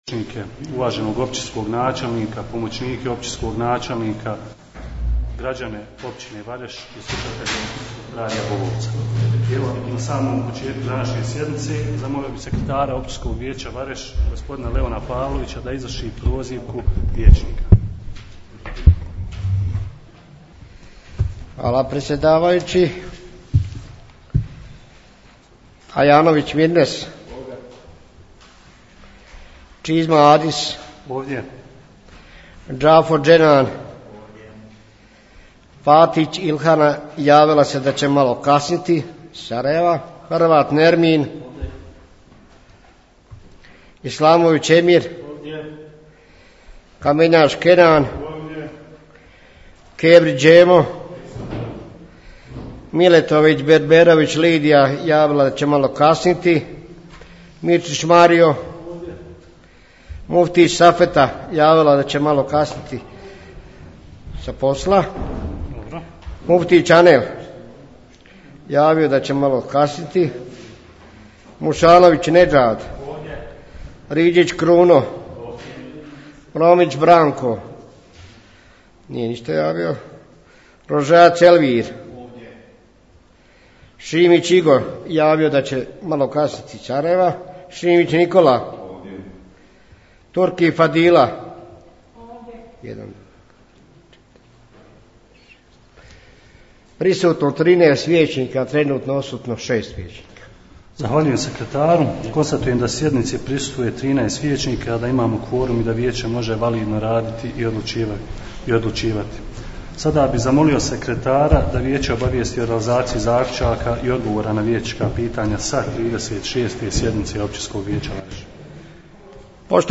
U utorak 30.06.2020. godine održana je 37. sjednica Općinskog vijeća Vareš, poslušajte tonski snimak...